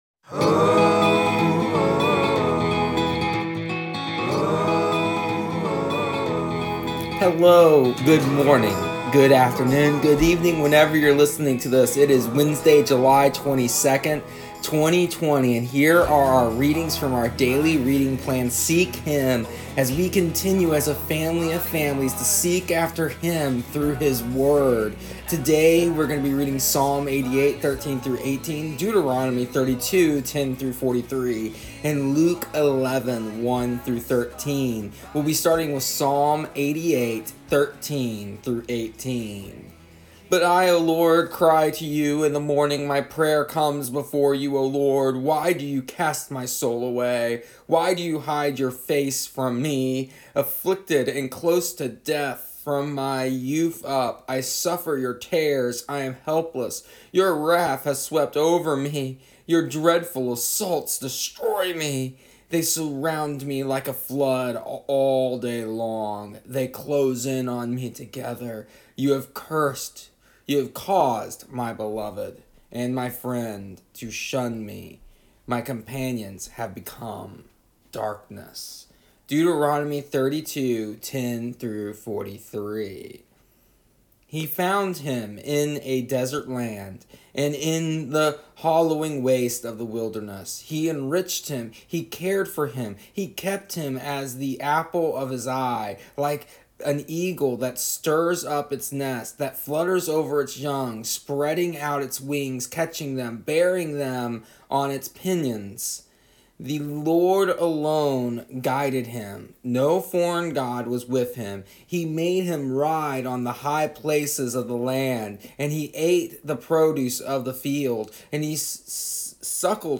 Here is an audio version of our daily readings from our daily reading plan Seek Him for July 22nd, 2020.